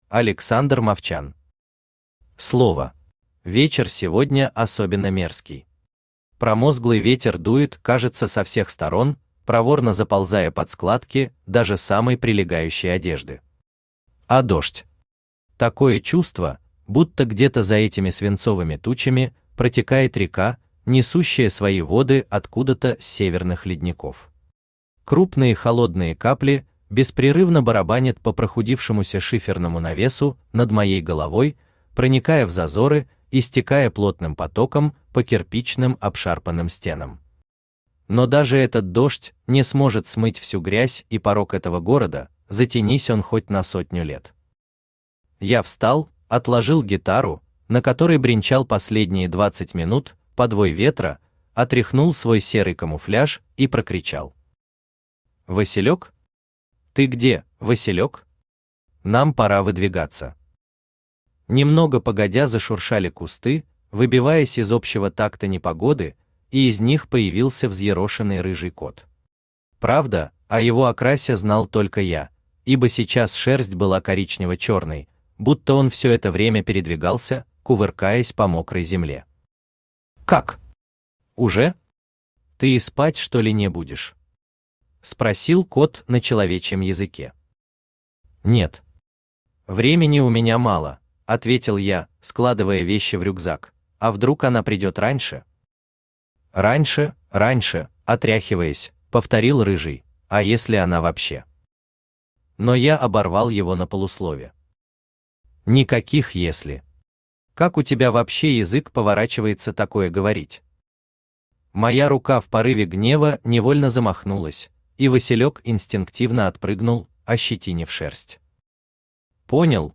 Аудиокнига Слово | Библиотека аудиокниг
Aудиокнига Слово Автор Александр Юрьевич Мовчан Читает аудиокнигу Авточтец ЛитРес.